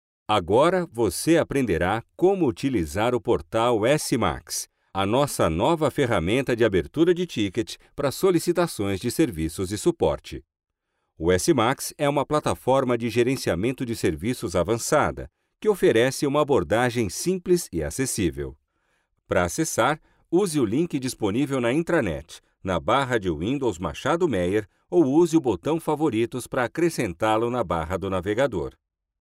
Kommerziell, Natürlich, Zuverlässig, Freundlich, Corporate
Unternehmensvideo
His voice is gentle, friendly and conveys reliability.